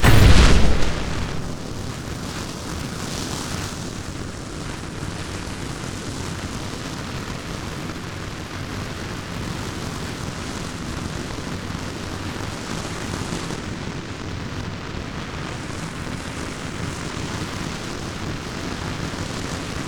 ZombieSkill_SFX
sfx_skill 01.wav